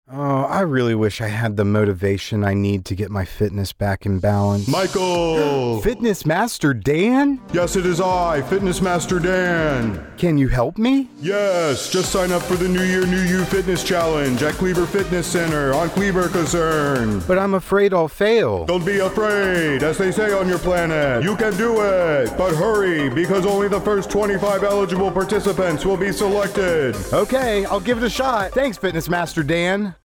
Radio Spot - New Year New You Fitness Challenge